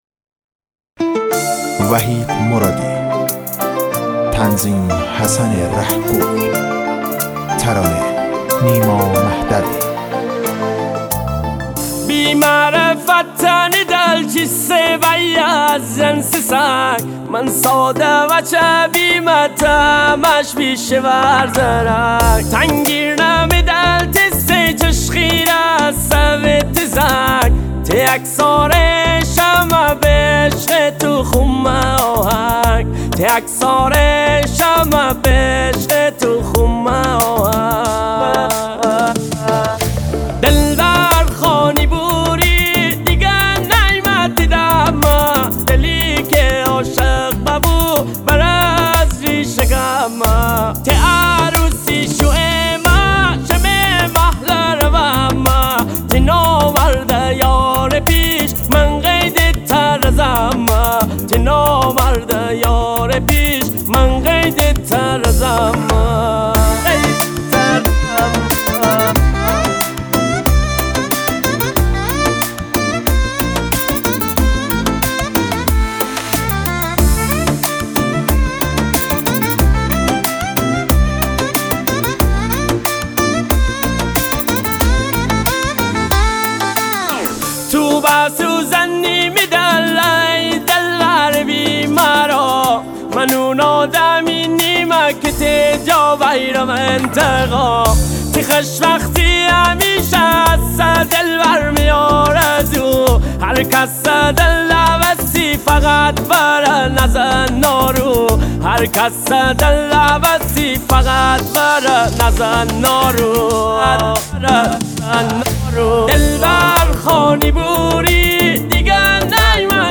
غمگین
با سبک غمگین مازندرانی